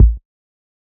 Metro Classic Kick.wav